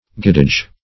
Search Result for " guidage" : The Collaborative International Dictionary of English v.0.48: Guidage \Guid"age\, n. [See Guide.] 1.